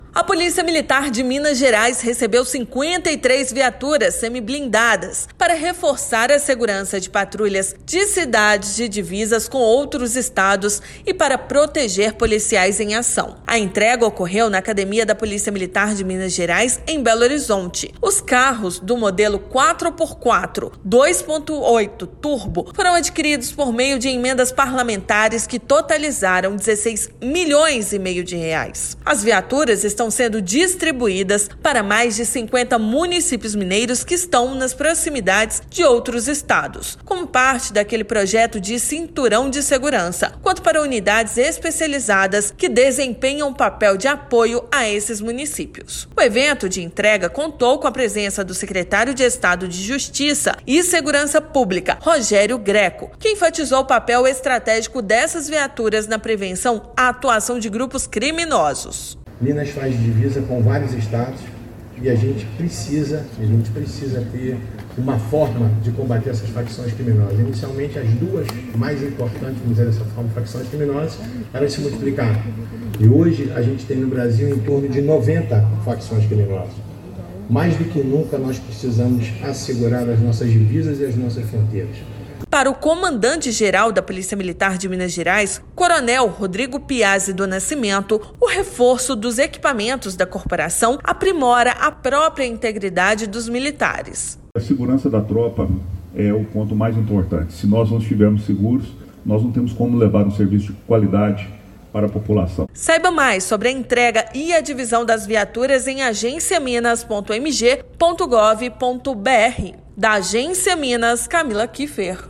Veículos com segurança reforçada ajudarão a patrulhar cidades nas divisas com outros estados e proteger policiais em ação. Ouça matéria de rádio.